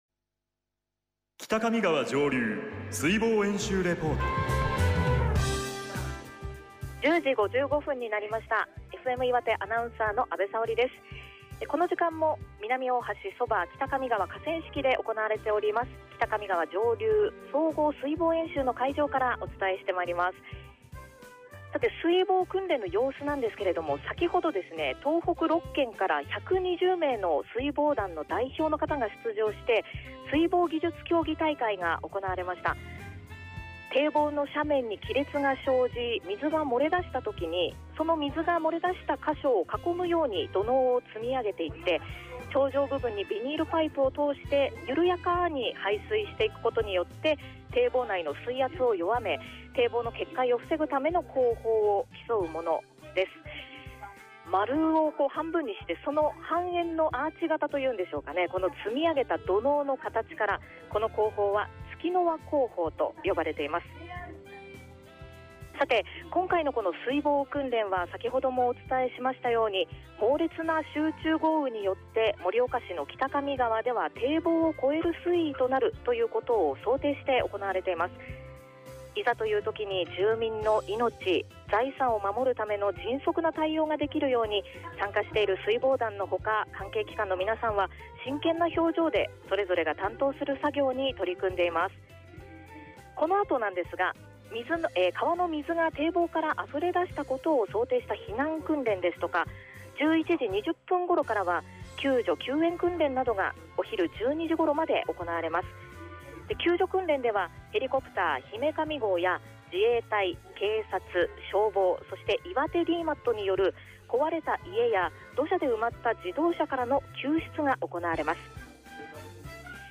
２０１５北上川上流総合水防演習　実況レポート　【提供：潟Gフエム岩手】
B５月２４日（日）09：55　レポート